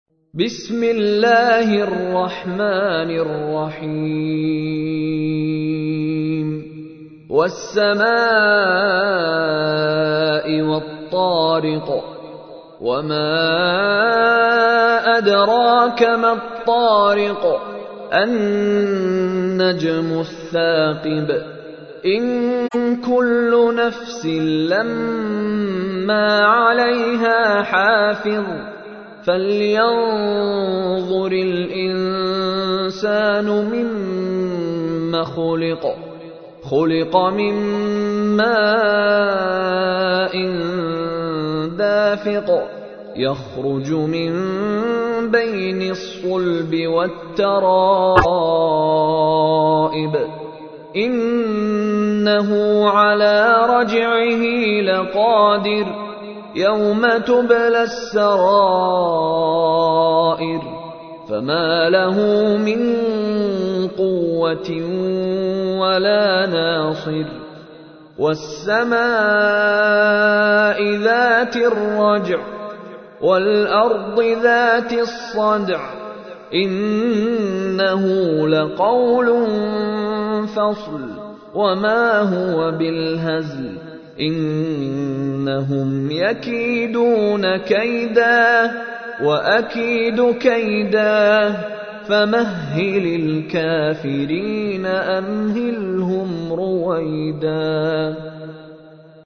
تحميل : 86. سورة الطارق / القارئ مشاري راشد العفاسي / القرآن الكريم / موقع يا حسين